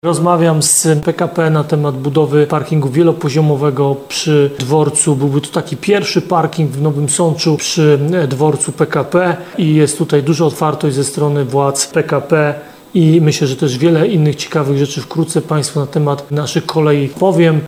Myślę, że też wiele innych ciekawych rzeczy wkrótce Państwu na temat naszych kolei powiem – mówił prezydent Ludomir Handzel w trakcie spotkania on-line z mieszkańcami.